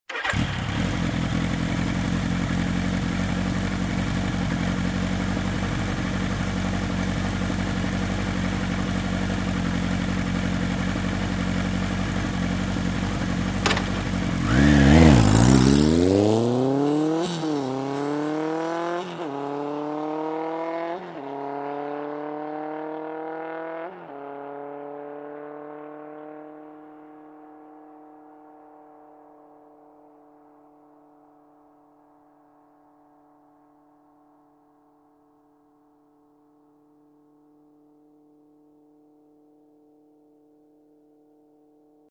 Мотоцикл заводится, ожидает, быстро уезжает
• Категория: Мотоциклы и мопеды
• Качество: Высокое